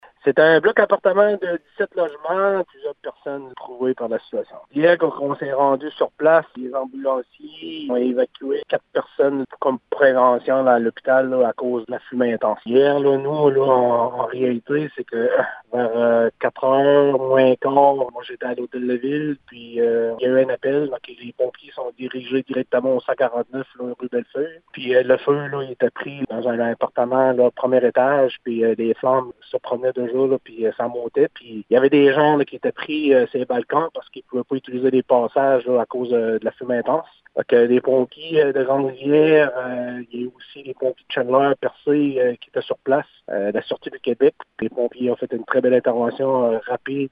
Les précisions avec Gino Cyr, maire de Grande-Rivière: